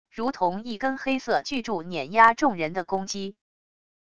如同一根黑色巨柱碾压众人的攻击wav音频